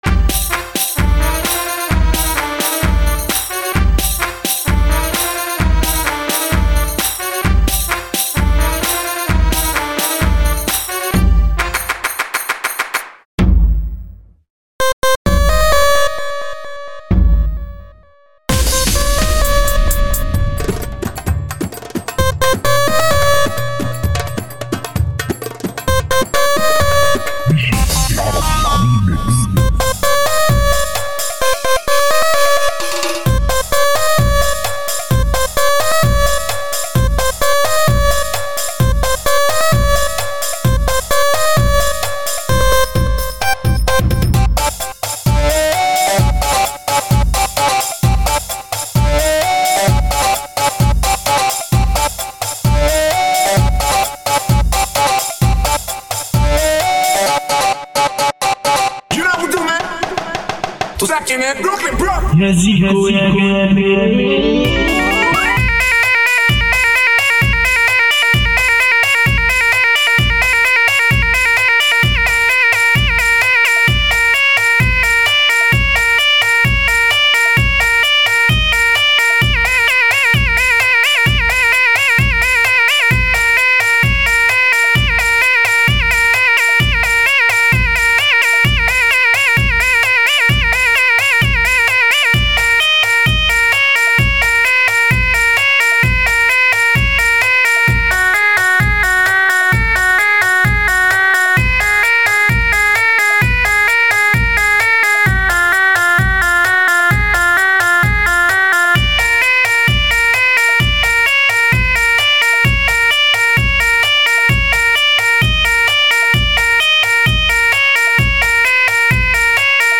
مزمار